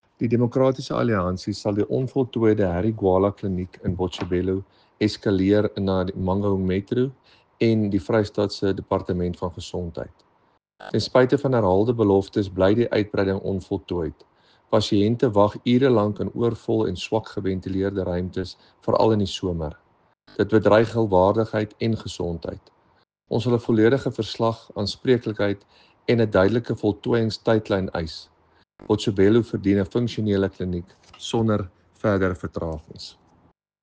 Afrikaans soundbite by David van Vuuren MPL.